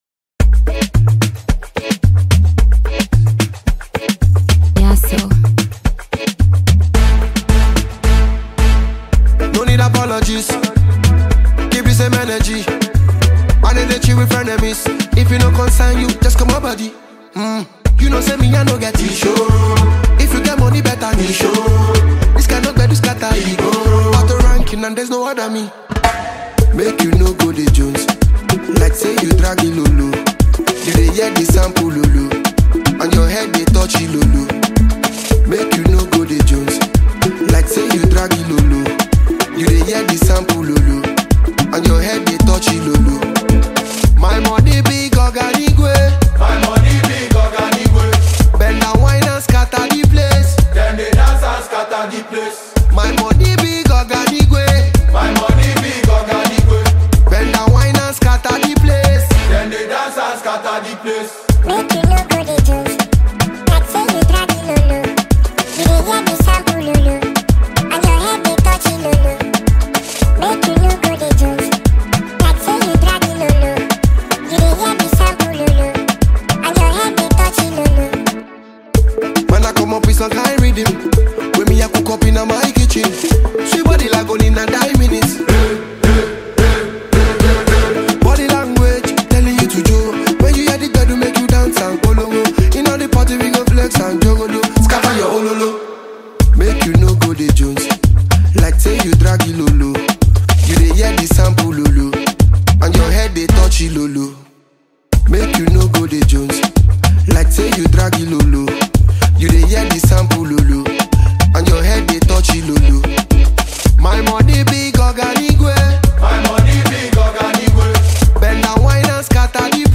South African Maskandi Musician
single